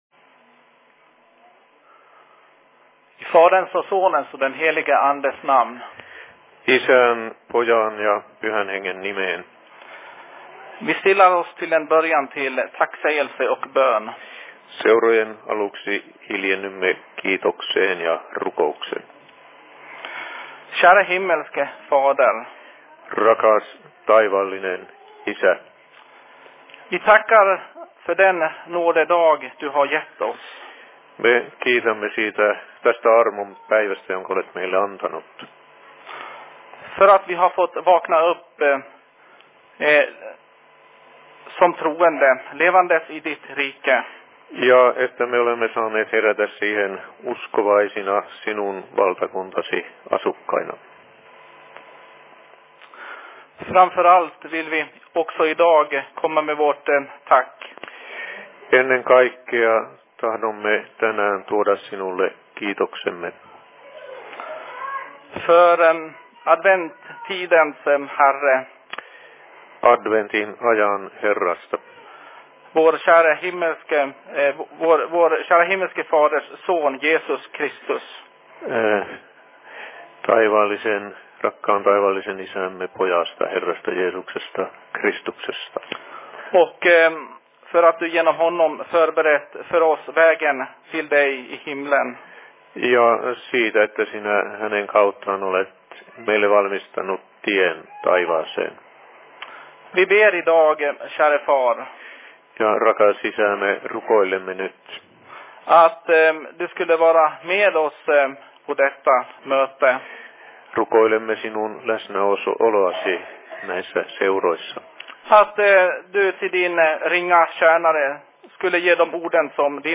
Se Fi Predikan I Dalarnas Fridsförening 04.12.2011
Simultaanitulkattu Ruotsi, Suomi